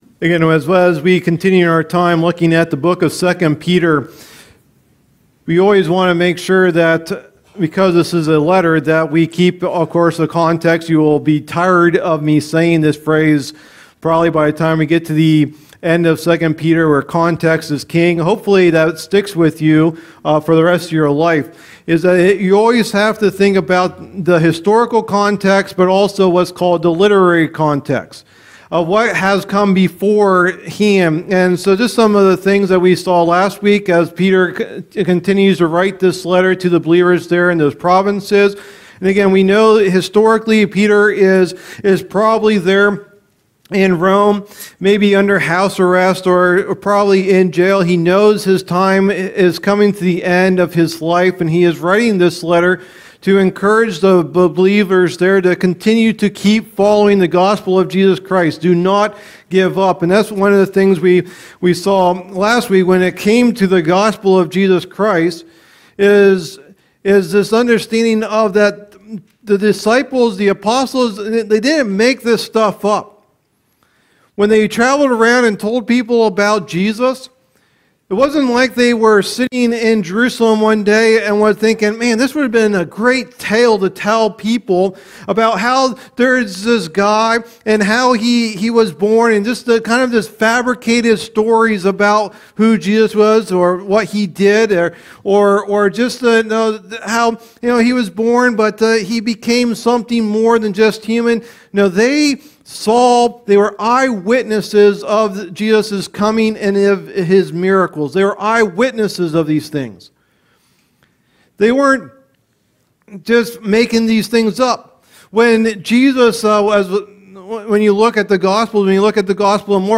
Sunday Morning Teachings | Bedford Alliance Church
Sermon